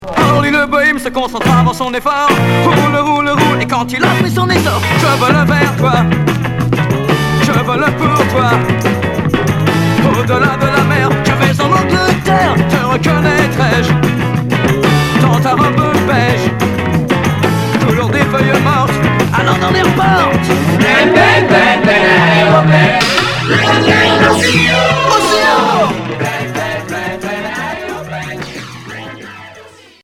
Beat psyché